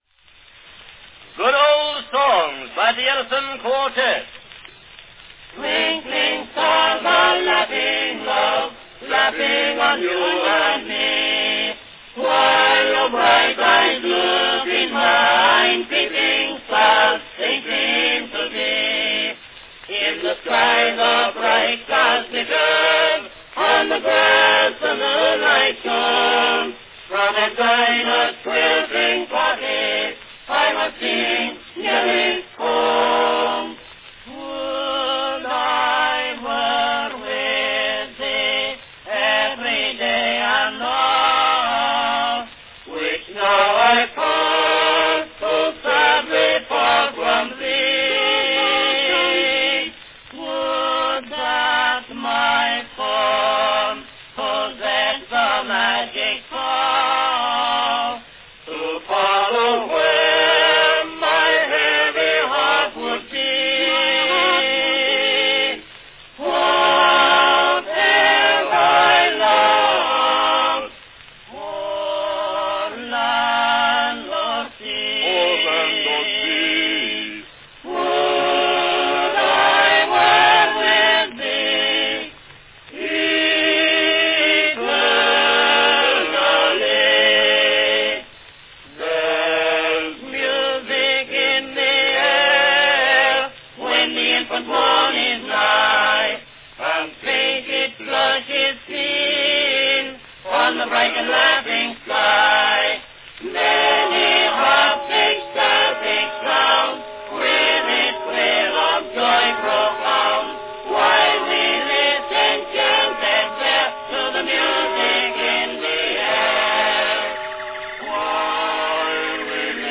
Category Quartette
The singers are unaccompanied.